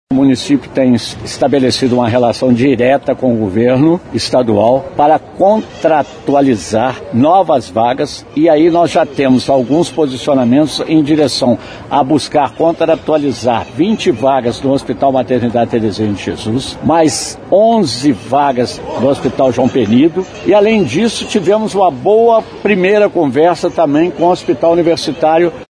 Atualmente a cidade tem 20 leitos disponíveis na maternidade Therezinha de Jesus e 11 no João Penido. Antonio Almas explicou como está a infraestrutura para atendimento dos pacientes que precisarem de leitos em hospitais.